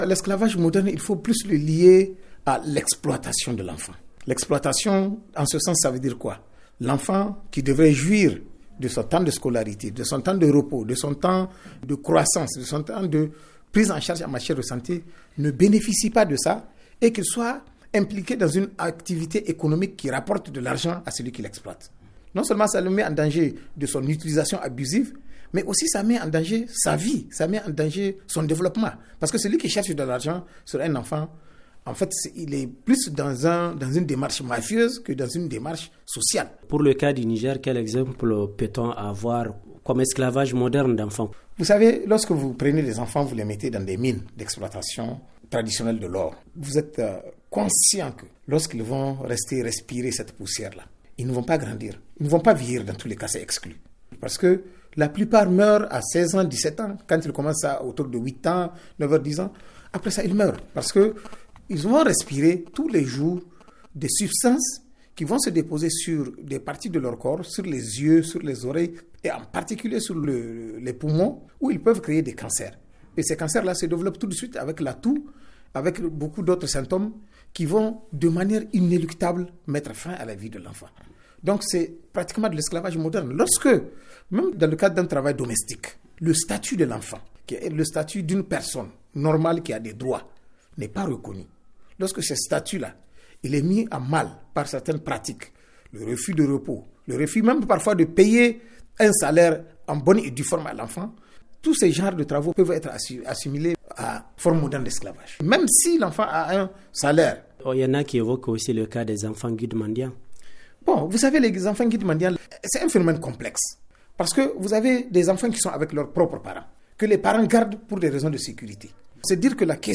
FR Interview de